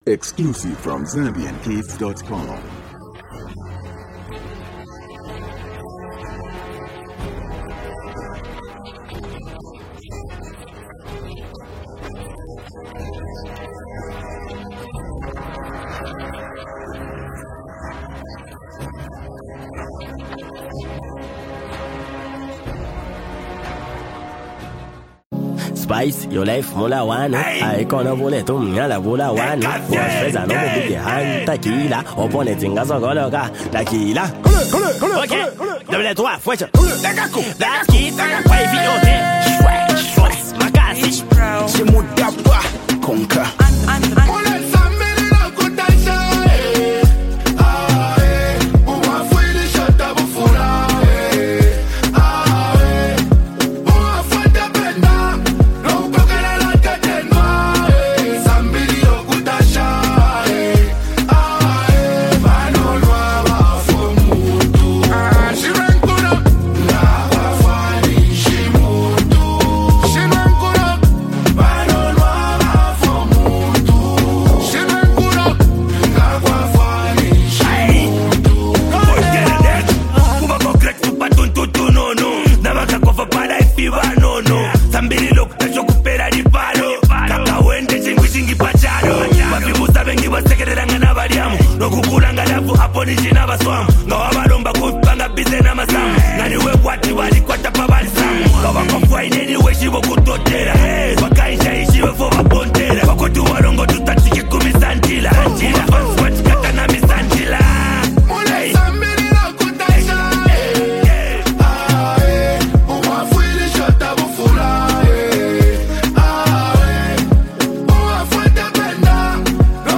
signature smooth and soulful vocals
sharp and witty rap delivery
melodic and catchy voice